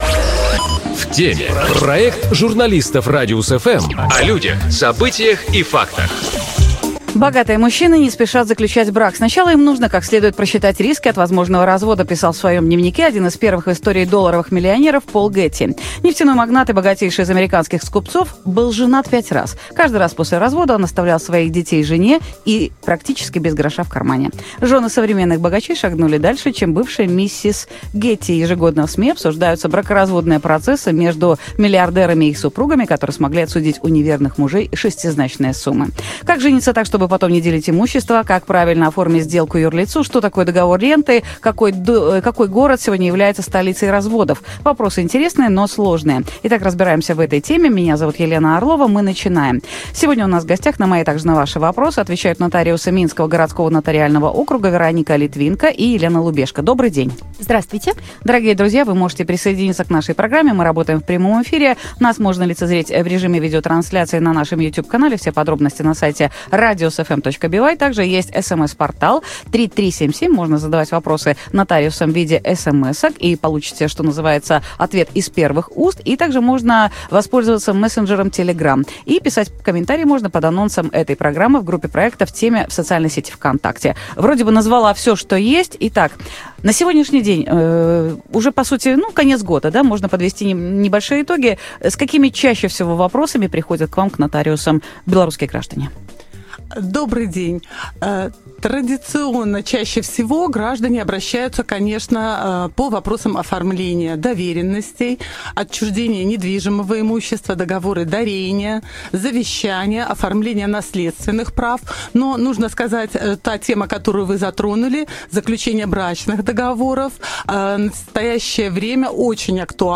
На эти и другие вопросы отвечают нотариусы минского городского нотариального округа